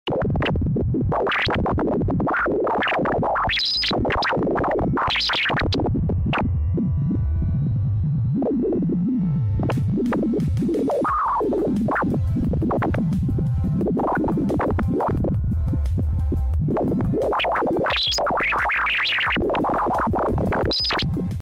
The sound of plasma within sound effects free download
The sound of plasma within the solar wind.